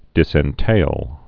(dĭsĕn-tāl)